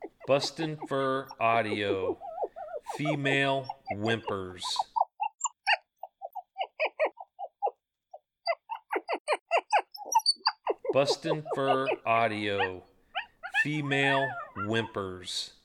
BFA Female Whimpers
BFA's Alpha Female Coyote Beans, greeting whimpering to Apollo, BFA's Alpha Male Coyote.
BFA Female Whimpers Sample.mp3